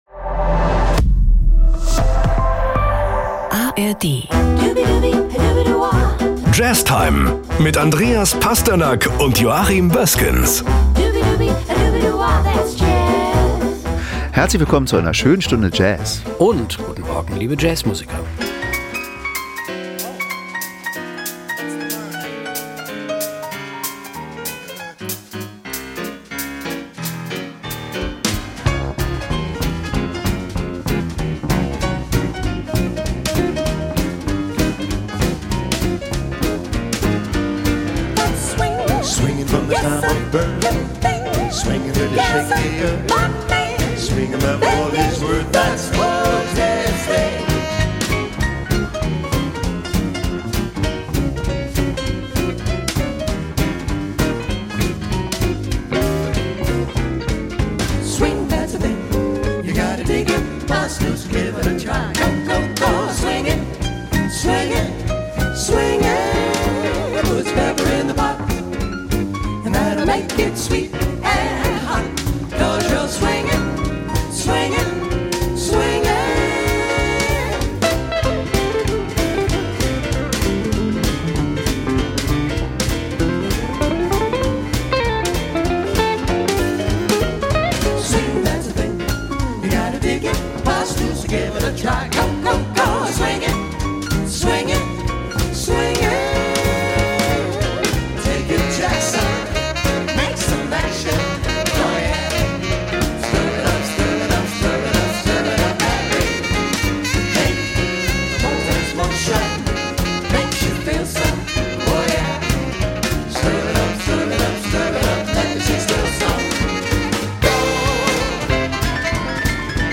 Nachrichten aus Mecklenburg-Vorpommern - 03.05.2025